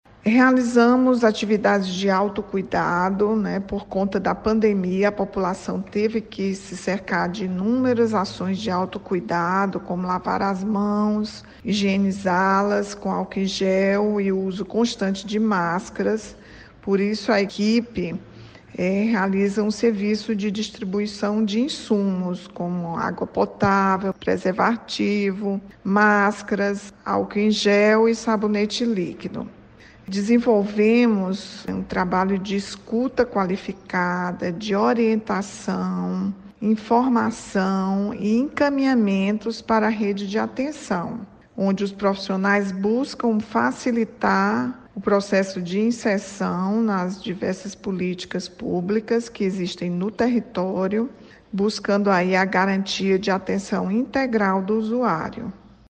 A secretária-executiva de políticas sobre drogas da SPS, Rachel Marques, fala do cuidado e atenção que as pessoas recebem.